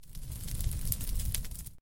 fire.mp3